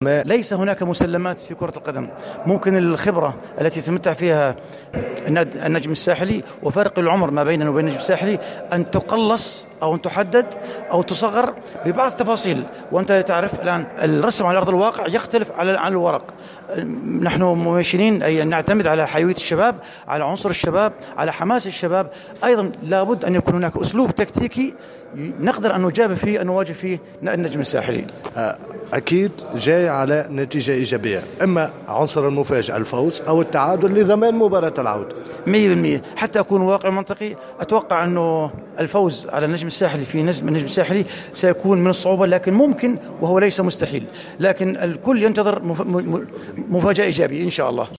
ندوة صحفية للحديث حول مواجهة النجم الساحلي المبرمجة ليوم السبت 11 أوت 2018 في الملعب الأولمبي بسوسة لحساب الجولة الأولى من منافسات كأس العرب للأندية.